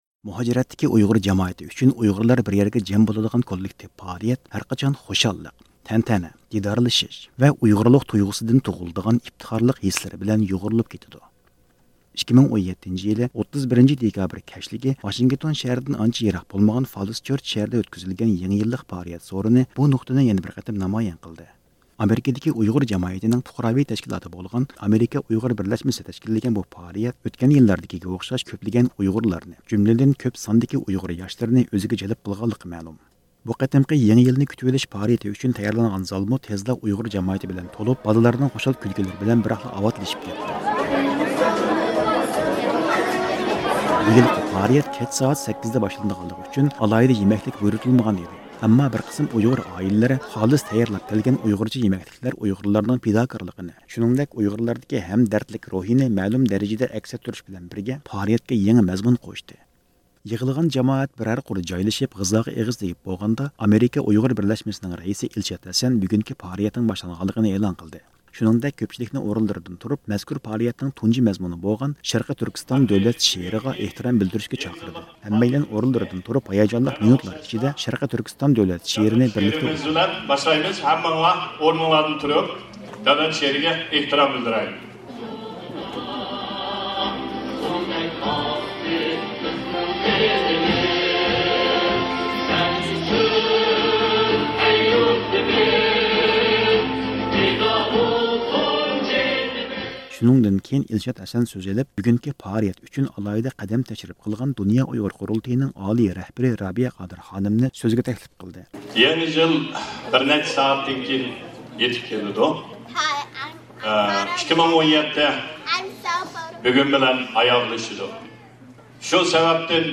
ئەنئەنىۋى ئۇيغۇر ناخشا-مۇزىكىلىرى بىلەن جانلانغان يېڭى يىللىق سورۇندا ئۇيغۇر پائالىيەتچىلەر سۆز قىلىپ، يېڭى يىلنىڭ ئۇيغۇرلار ئۈچۈن يېڭى ئۈمىدلەردىن دېرەك بېرىدىغانلىقىنى بىلدۈردى.
بۇ قېتىمقى يېڭى يىلنى كۈتۈۋېلىش پائالىيىتى ئۈچۈن تەييارلانغان زالمۇ تېزلا ئۇيغۇر جامائىتى بىلەن تولۇپ، بالىلارنىڭ خوشال كۈلكىلىرى بىلەن بىراقلا ئاۋاتلىشىپ كەتتى.
كېچە سائەت قوڭغۇرىقى نۆلگە يېقىنلاشقاندا كۆپچىلىك بىرلىكتە تەتۈر ساناق ساناپ يېڭى يىلنى بىرلىكتە كۈتۈۋالدى.